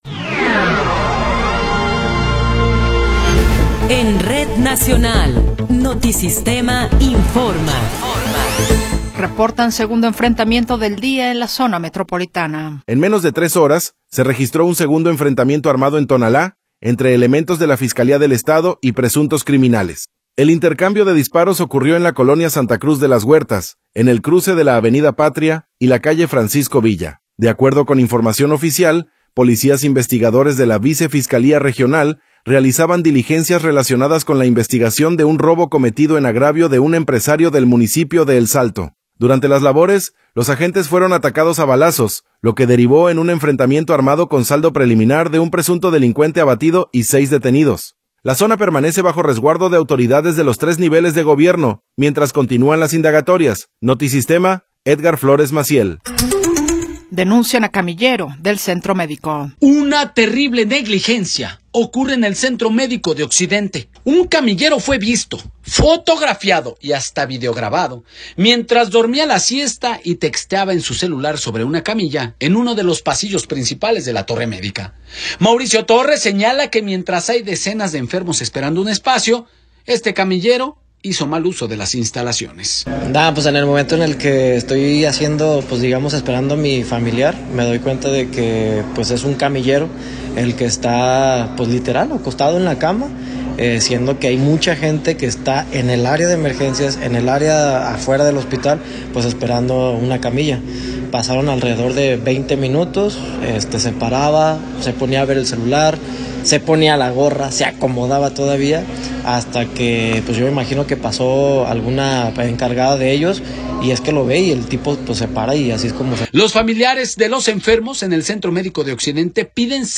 Noticiero 16 hrs. – 9 de Febrero de 2026